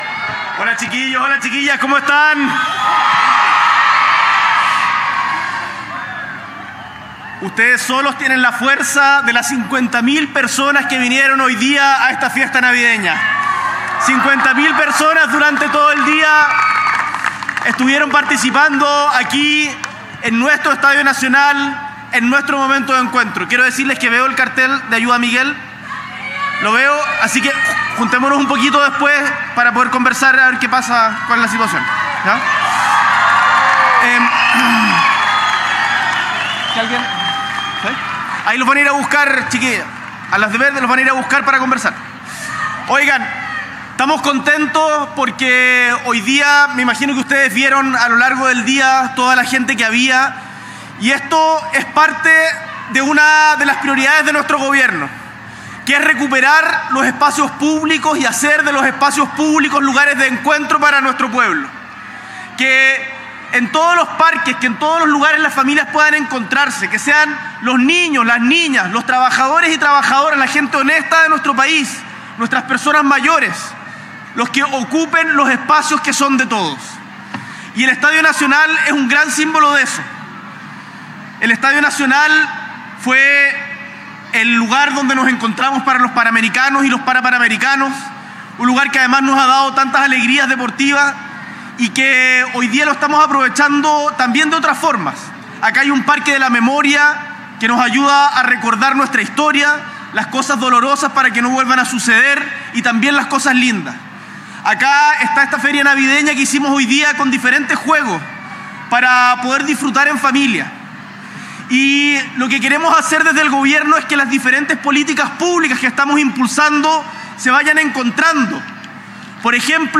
S.E. el Presidente de la República, Gabriel Boric Font, participa del Festival Navideño 2024